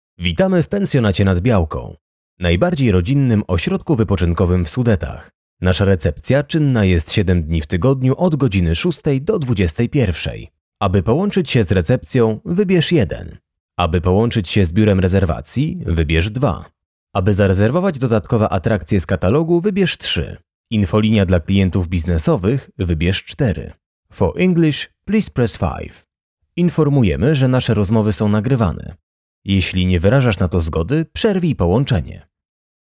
Male 30-50 lat
Young but mature voice whose versatile timbre works well for many types of recordings.
Zapowiedź telefoniczna